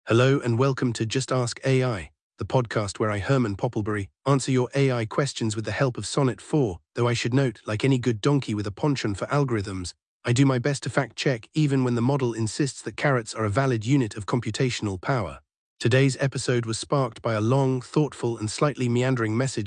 AI-Generated Content: This podcast is created using AI personas.
Hosts Herman and Corn are AI personalities.